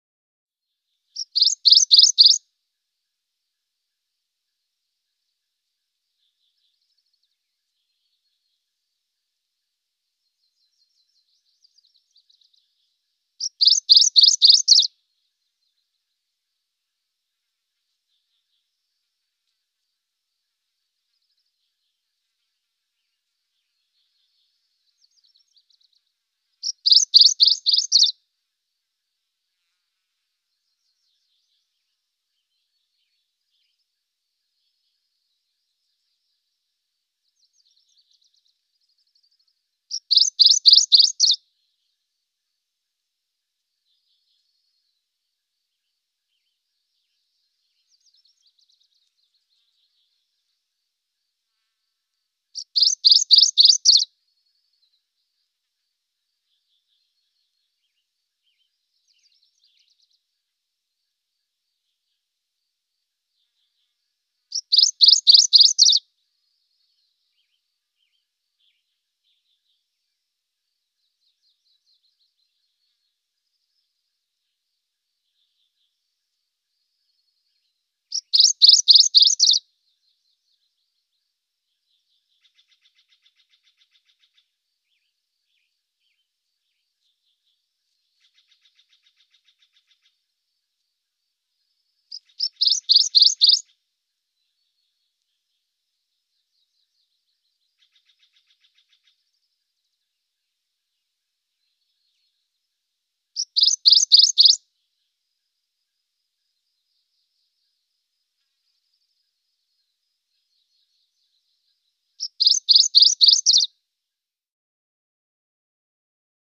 Warbler, Macgillivray's Chirps. Single Chirps With Very Distant Birds Chirping. Close Perspective.